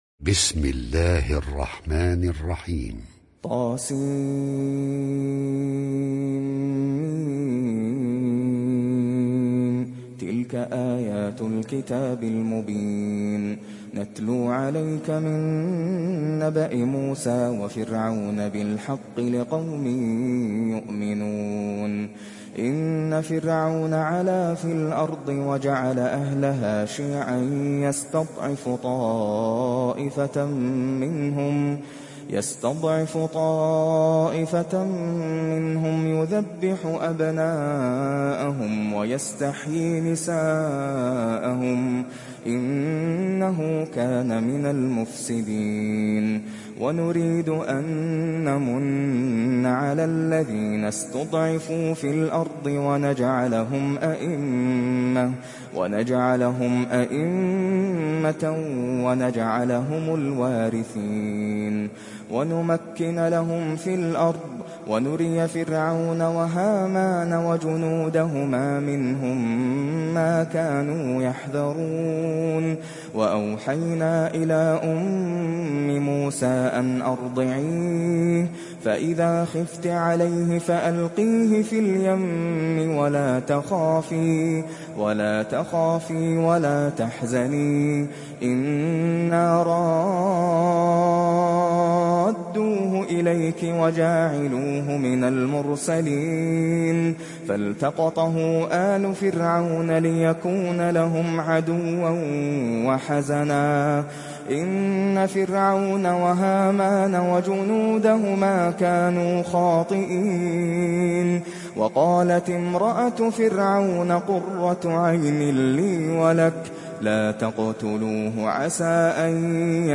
Surat Al Qasas Download mp3 Nasser Al Qatami Riwayat Hafs dari Asim, Download Quran dan mendengarkan mp3 tautan langsung penuh